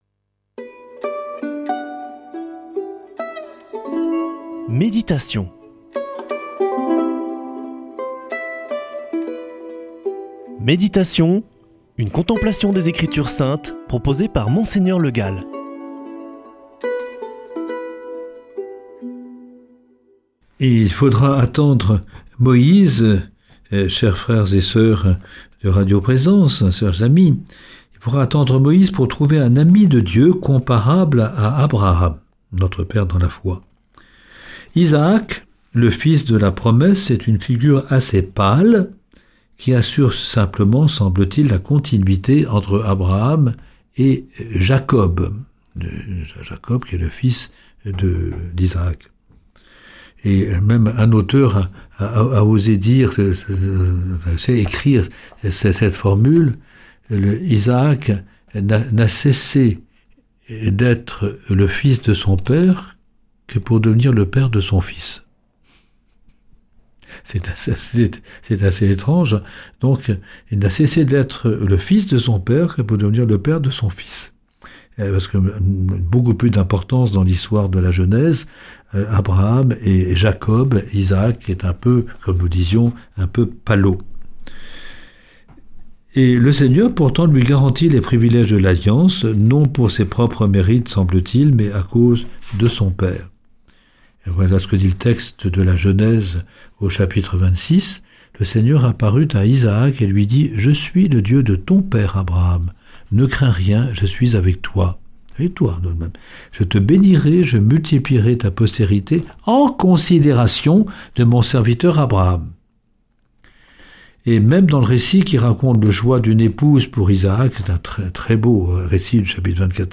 Méditation avec Mgr Le Gall